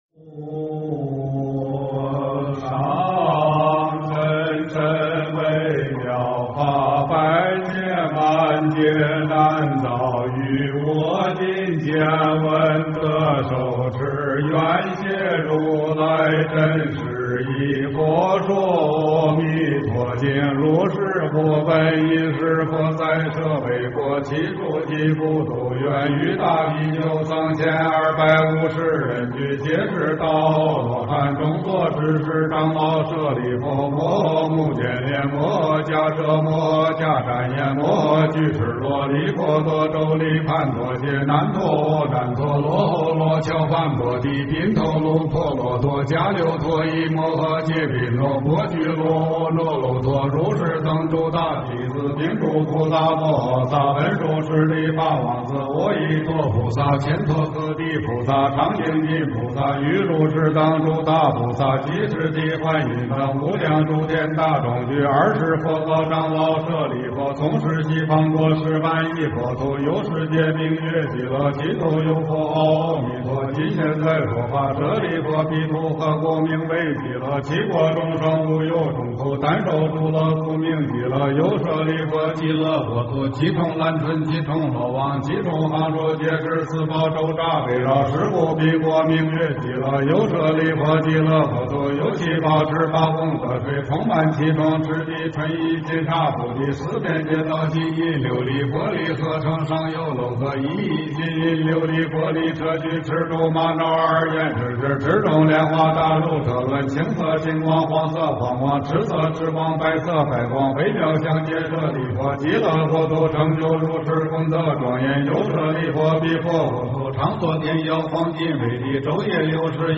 诵经 佛说阿弥陀经(功课版
佛音 诵经 佛教音乐 返回列表 上一篇： 发菩提心经论卷上 下一篇： 功德宝山神咒 相关文章 晚安曲 晚安曲--海涛法师...